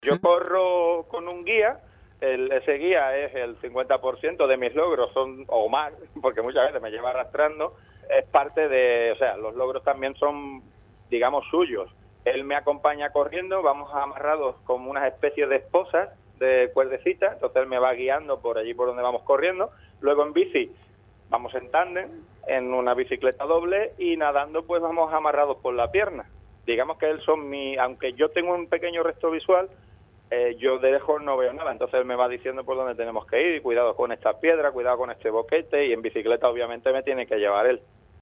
con su tono campechano (formato MP3).